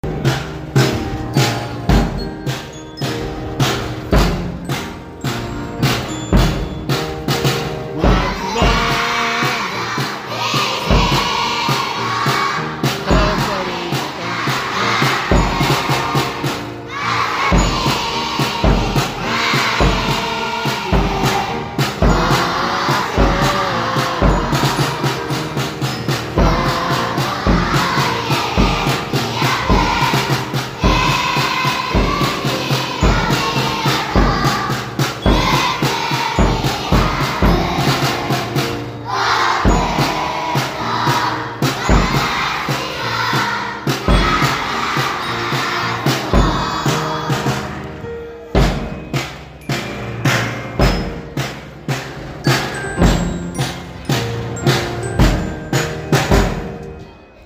0502♪迎える会で歌った校歌♪
こうか.mp3